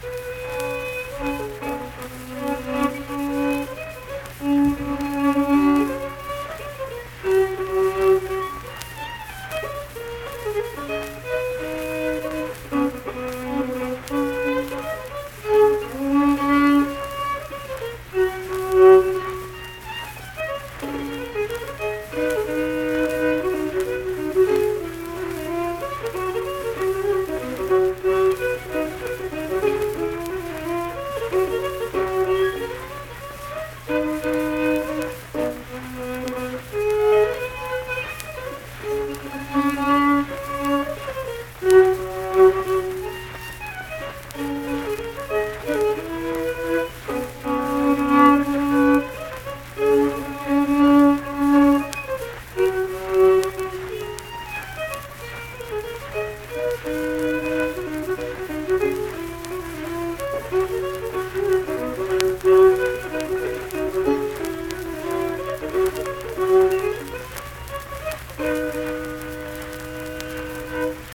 Unaccompanied fiddle music
Performed in Ziesing, Harrison County, WV.
Instrumental Music
Fiddle